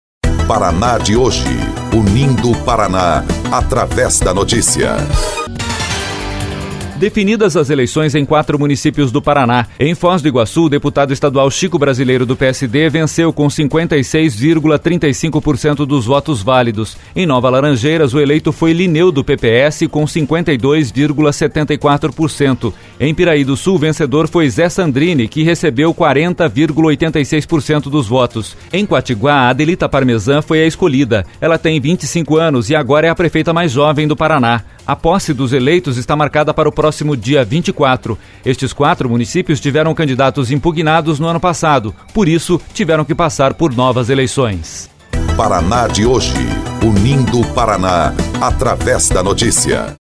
03.04 – Boletim – Quatro municípios escolhem novos prefeitos no Estado